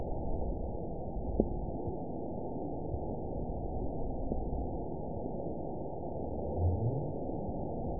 event 917907 date 04/21/23 time 17:17:31 GMT (2 years ago) score 9.31 location TSS-AB04 detected by nrw target species NRW annotations +NRW Spectrogram: Frequency (kHz) vs. Time (s) audio not available .wav